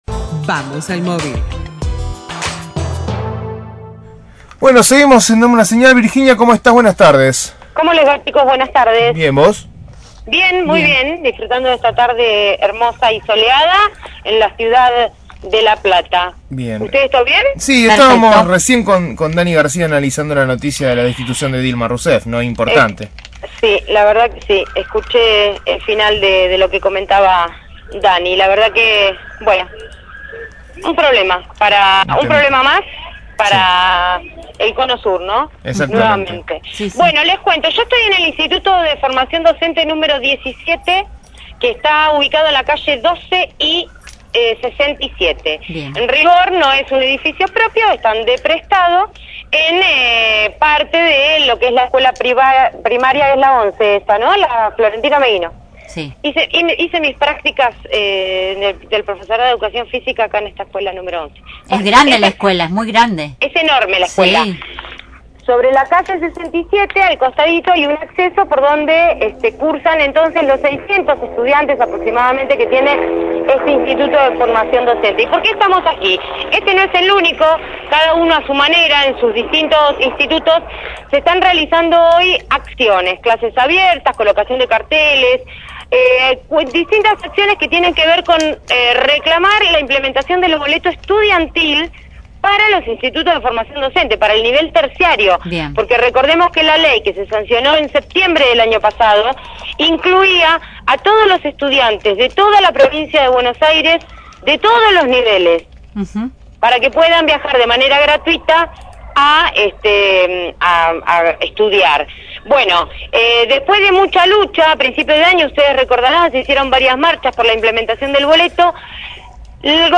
Móvil/ Reclamo por el boleto estudiantil en el ISFD nº 17 – Radio Universidad